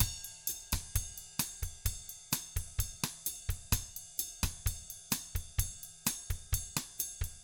129BOSSAT2-L.wav